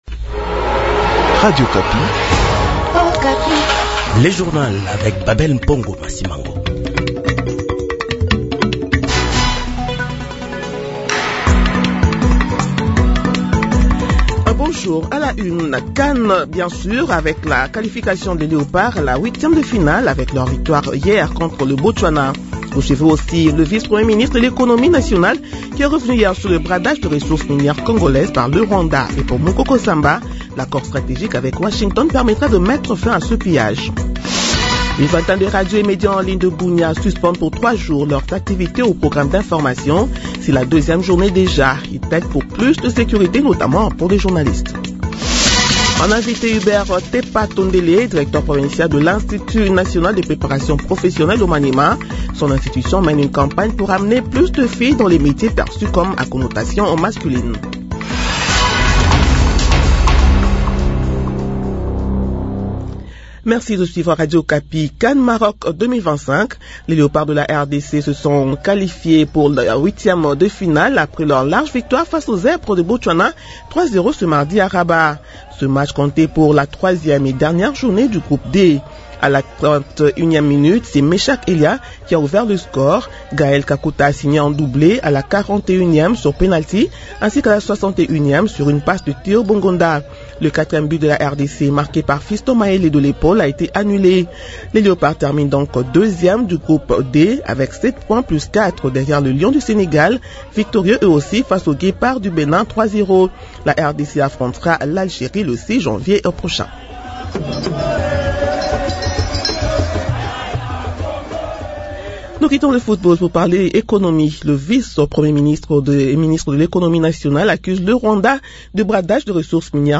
Journal de 8h de ce mercredi 31 décembre 2025